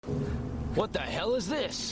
what the hell is this Meme Sound Effect